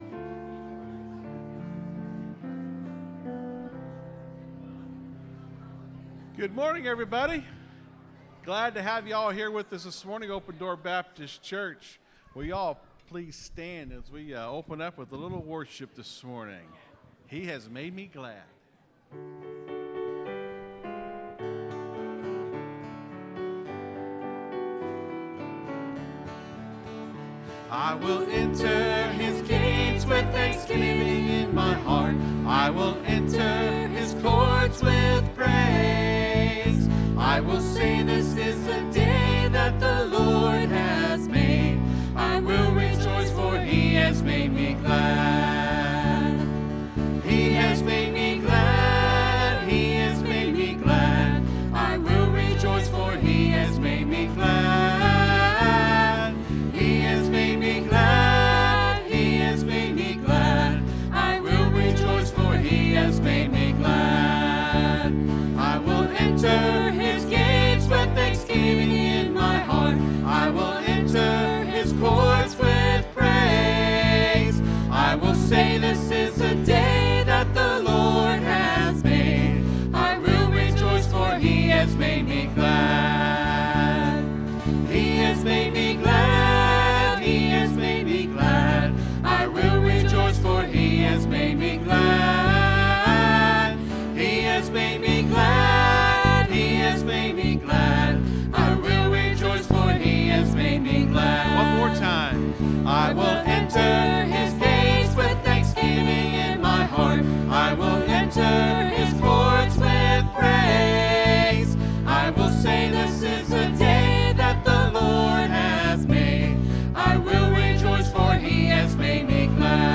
(Sermon starts at 24:00 in the recording).